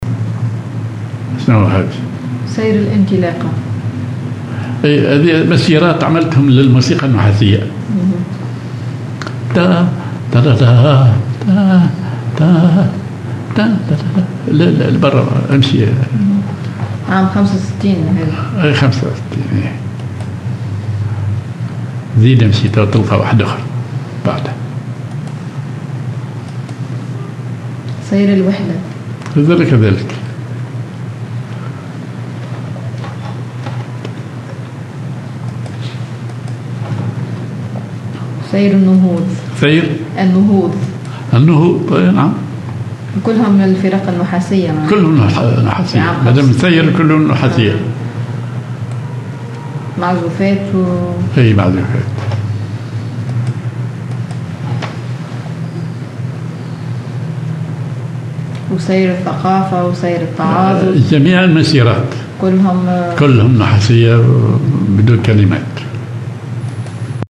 عجم على درجة الراست (أو دو كبير)
سير ذو نفس عسكري
genre أغنية